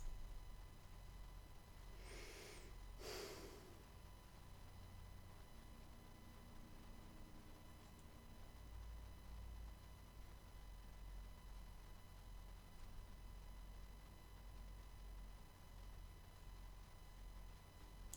Help! Blue Yeti high pitch noise - Page 3 - Windows - Audacity Forum
In a nutshell, I’ve got the exact same problems as everyone else, except to a lesser extent: Mine is exceedingly quieter.